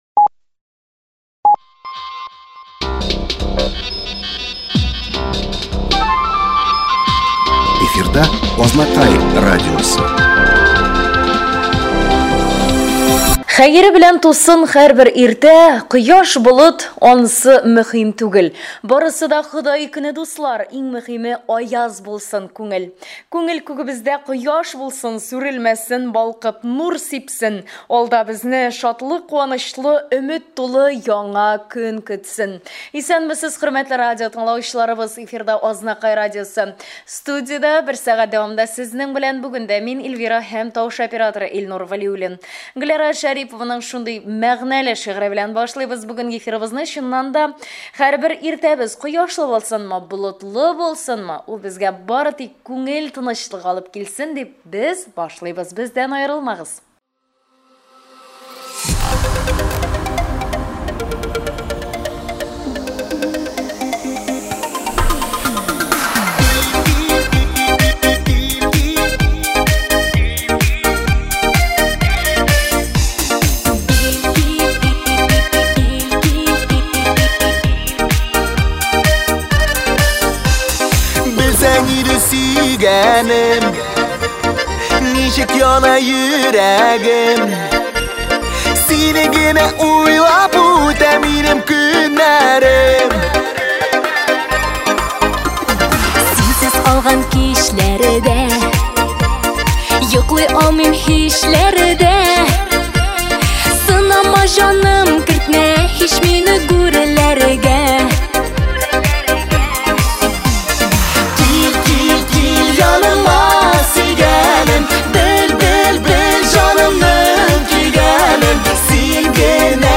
Азнакай радиосы дулкынында
Азнакай районының 5 июнь яңалыкларын тыңларга